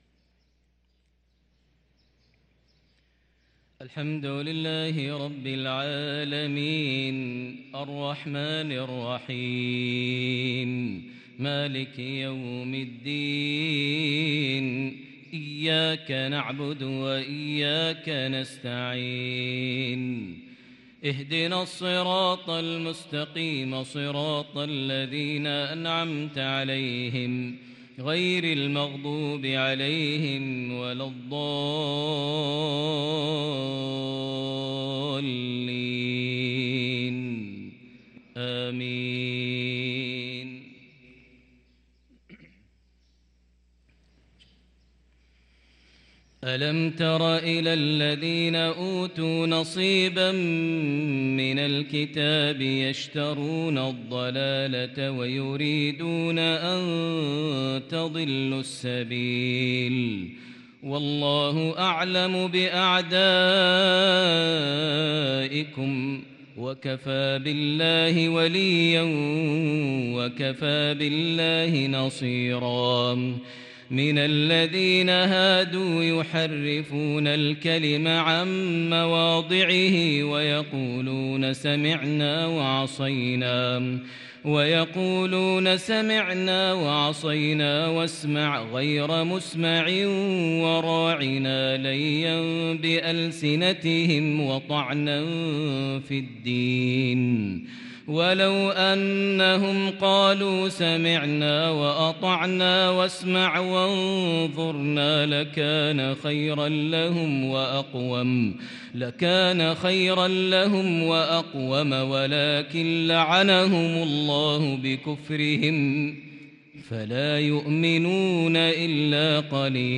تلاوة إبداعية تفوق الوصف من سورة النساء | فجر الأربعاء ٢٦ محرم ١٤٤٤هـ > 1444 هـ > الفروض - تلاوات ماهر المعيقلي